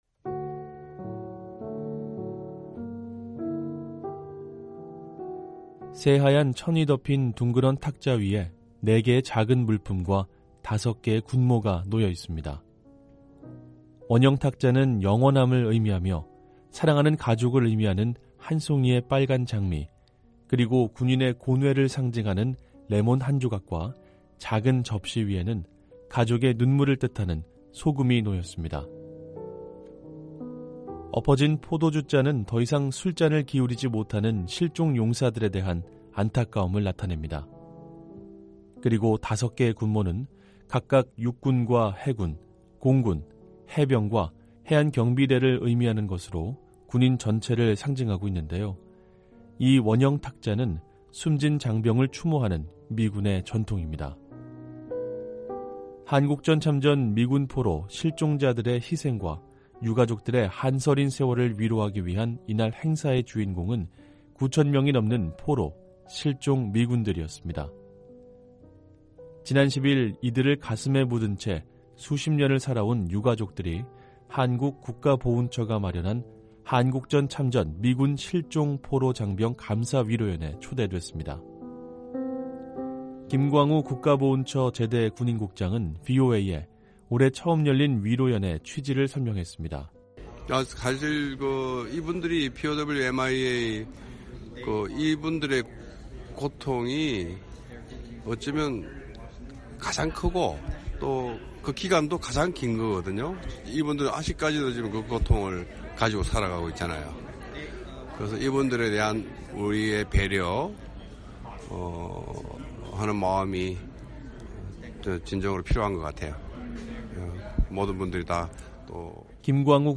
한 주 간 북한 관련 화제성 뉴스를 전해 드리는 ‘뉴스 풍경’ 시간입니다. 한국전쟁에서 포로가 됐거나 실종된 미군 가족을 위한 위로연이 워싱턴에서 열렸습니다.